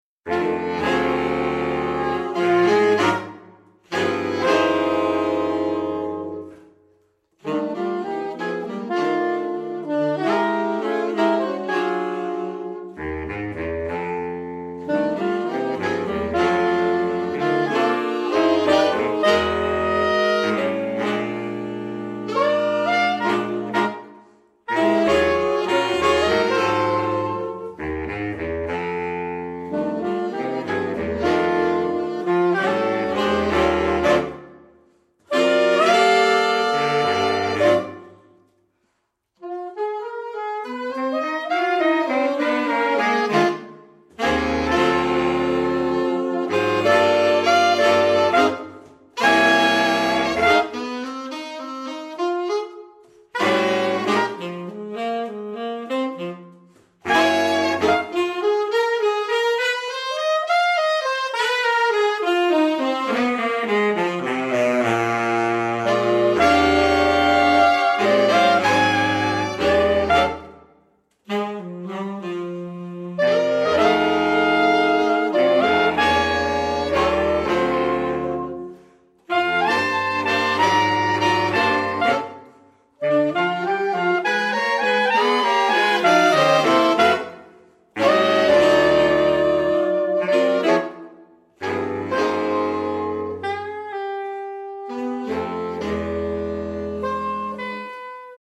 Voicing: Saxophone Quintet